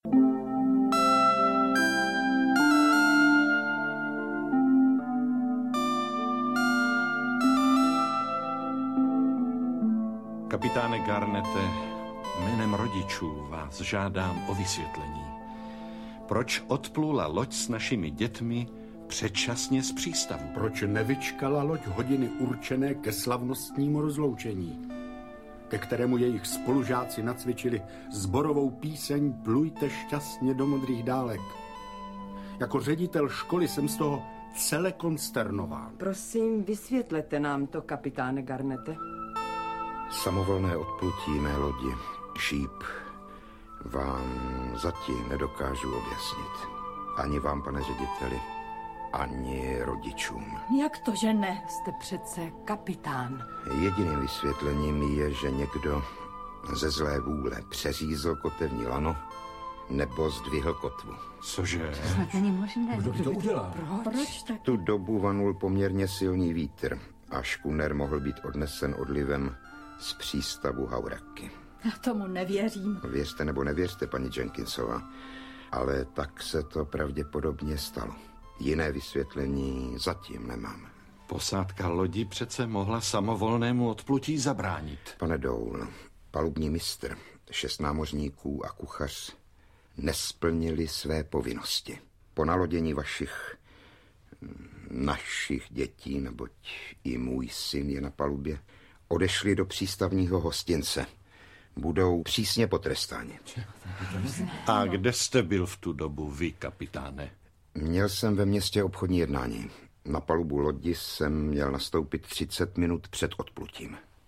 Dva roky prázdnin audiokniha
Ukázka z knihy